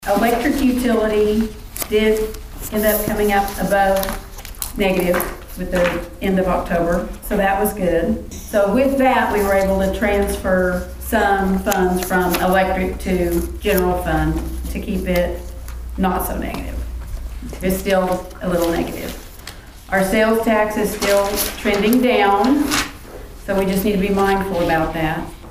At Thursday evening's council meeting in Pawhuska, City Manager Carol Jones gave an update on the city's financial status and the progress being made on the 2024 fiscal year audit.